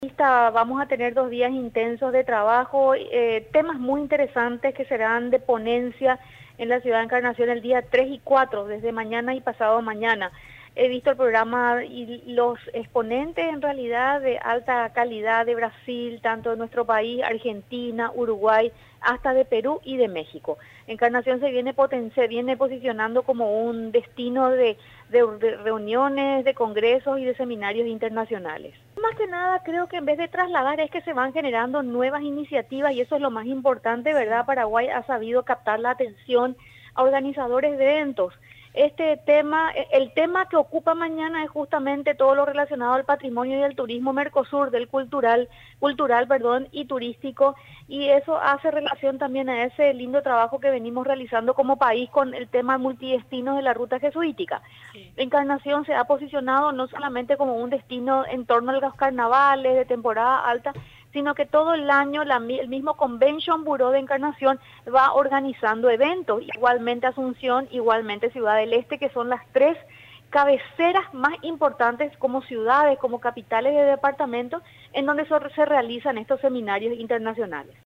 En el encuentro se hablará principalmente sobre patrimonio y turismo jesuíticos, informó la ministra de Turismo, Marcela Bacigalupo.